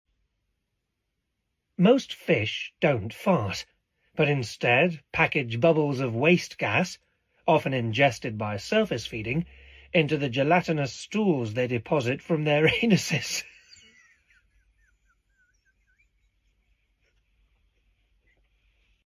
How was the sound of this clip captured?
Trying to get through this difficult passage of recording an audiobook!